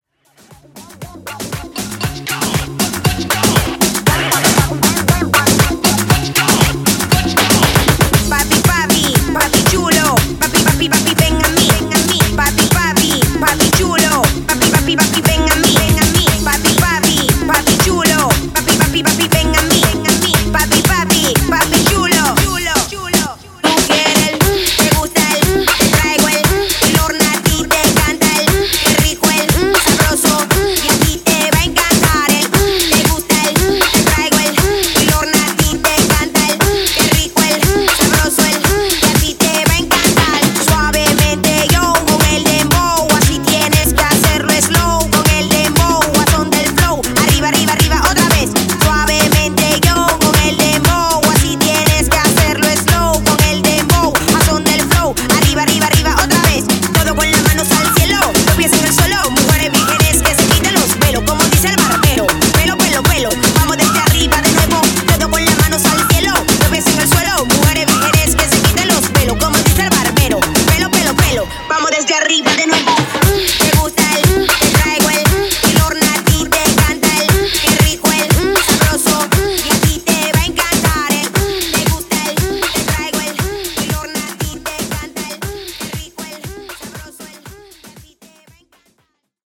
Afro Extended Mix)Date Added